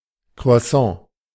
Das[1] Croissant (IPA: [kʁo̯aˈsɑ̃][2][3],